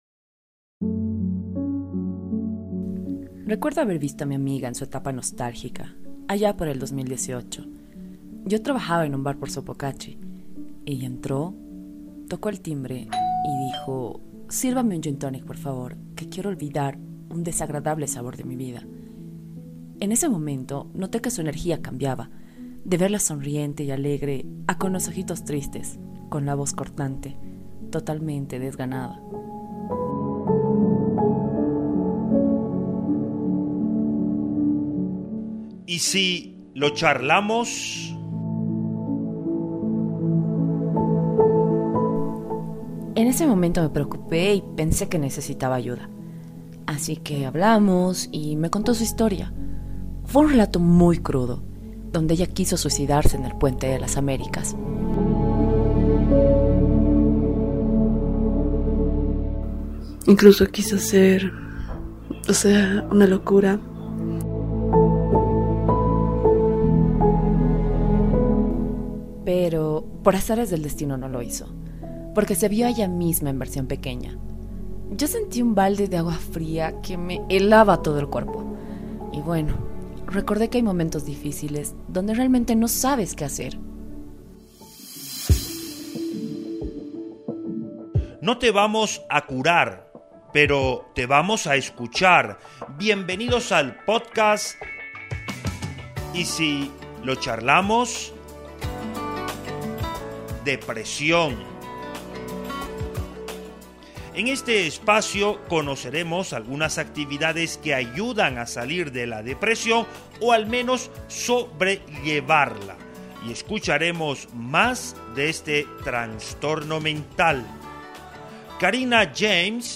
Esta serie de podcast transmitidos en línea aborda, desde la experiencia de las y los afectados y desde la voz autorizada de expertos, métodos alternativos para lidiar con la depresión. Otro de los objetivos es combatir el estigma que pesa sobre esta enfermedad.